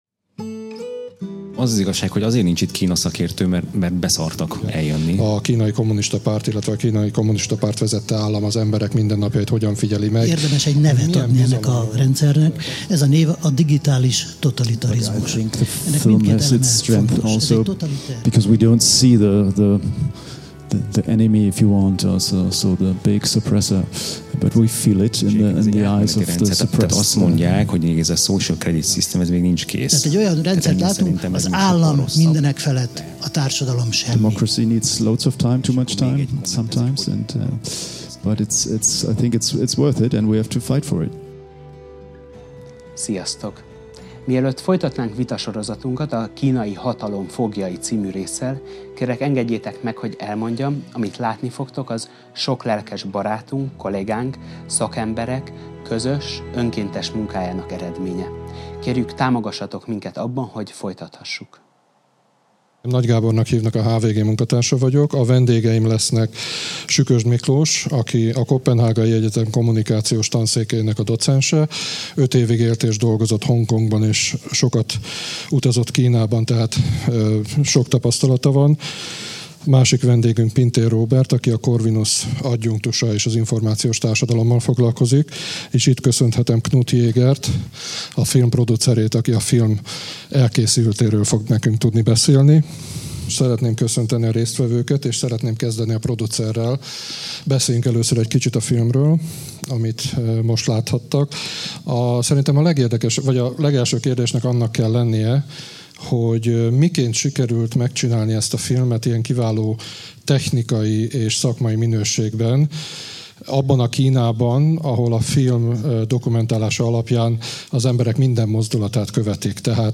Az adás helyenként angol beszédet tartalmaz.